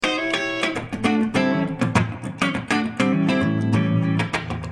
Free MP3 funk music guitars loops & sounds 1
Guitare loop - funk 27